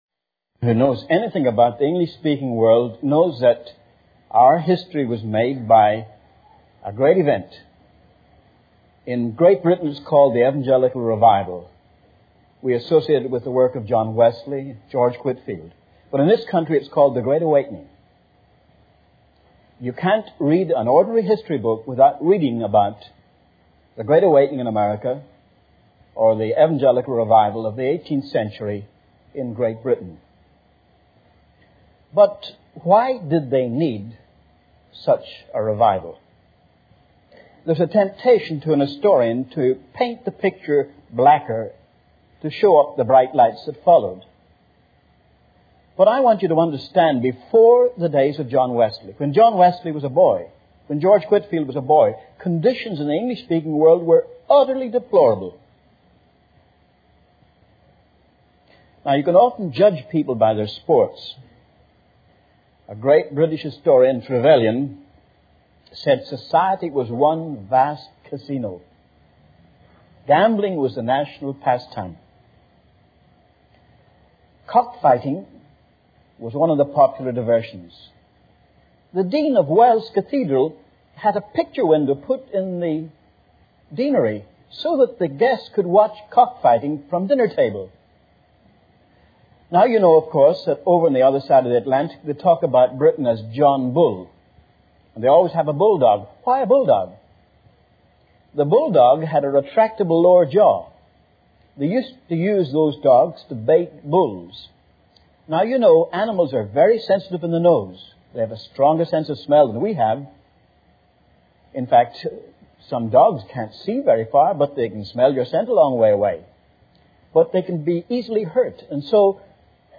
This sermon delves into the historical context of the Evangelical Revival in Great Britain and the Great Awakening in America during the 18th century, highlighting the societal depravity, moral decay, and spiritual decline that necessitated these revivals. It explores the transformative impact of key figures like John Wesley and George Whitefield, the beginnings of the Methodist movement, and the widespread revival that reshaped the English-speaking world, leading people back to God.